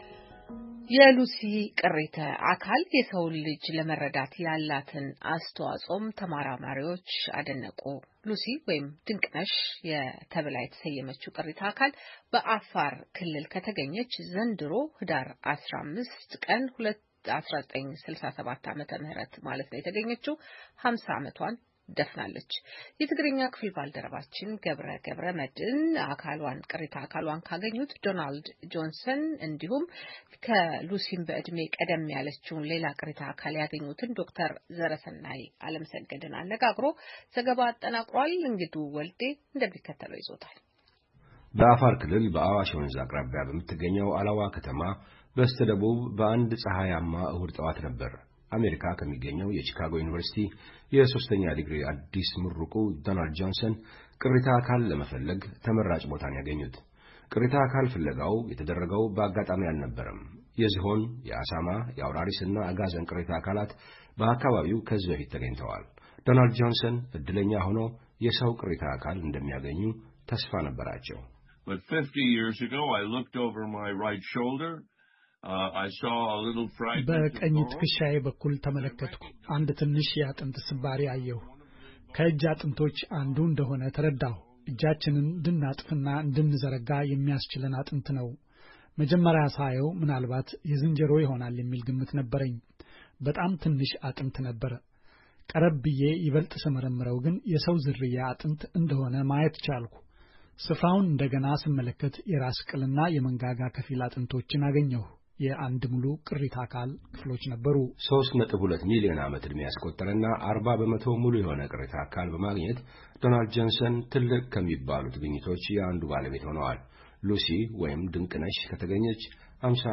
ሉሲ ወይም ድንቅነሽ ተብላ የተሰየመችው ቅሪተ አካል በአፋር ክልል ከተገኘች ዘንድሮ ኅዳር 15 ቀን 2017 ዓ.ም 50 ዓመቷን ደፍናለች፡፡ ቅሪተ አካሏን ካገኙት ዶናልድ ጆንሰን እንዲሁም፣ ከሉሲም በዕድሜ ቀደም ያለችውን ሌላ ቅሪተ አካል ያገኙትን ዶ/ር ዘረሰናይ ዓለምሰገድ አነጋግረናል።